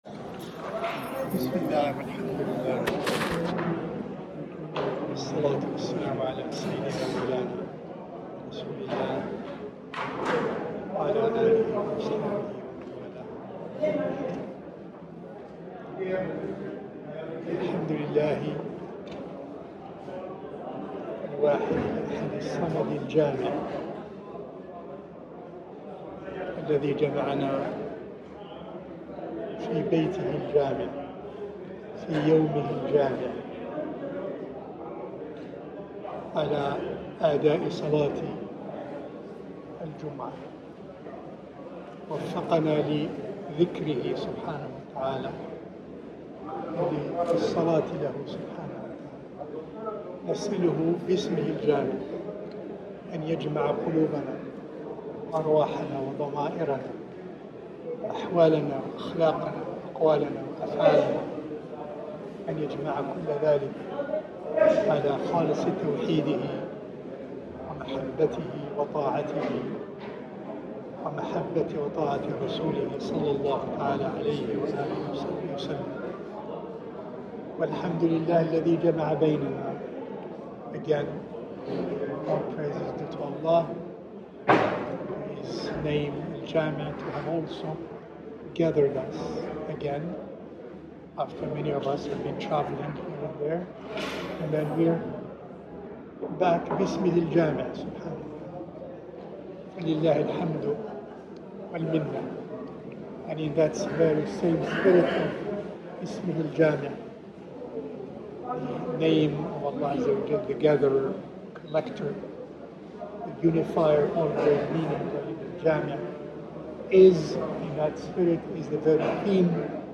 A Friday khatira (short talk) on living real brotherhood and sisterhood